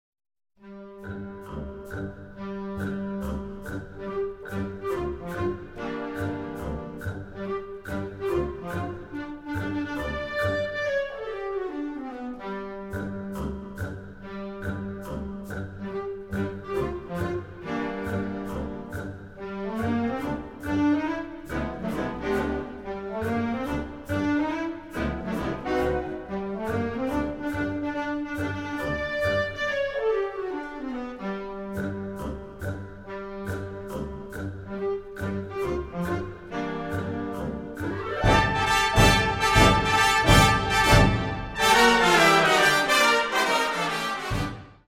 Bezetting Ha (harmonieorkest)
heldere, ietwat 'jazzy' suite